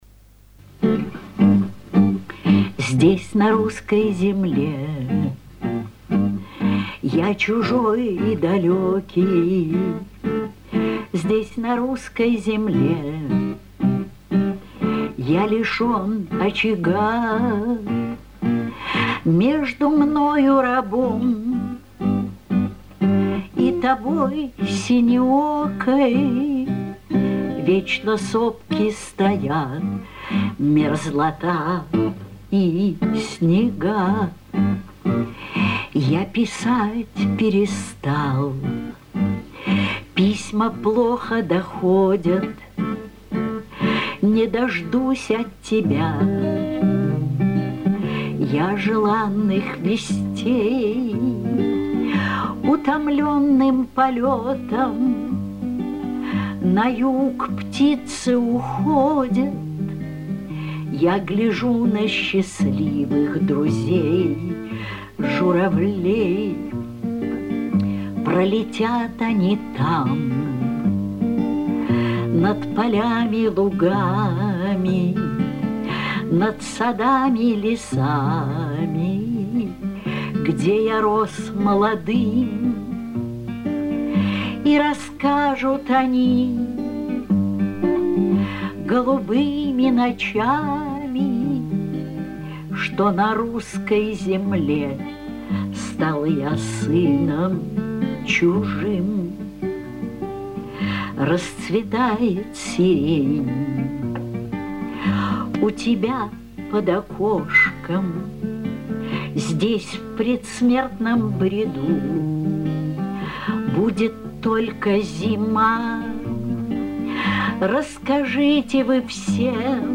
Песня исполнялась в "Гавани" в 2001 году...